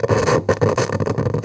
radio_tv_electronic_static_08.wav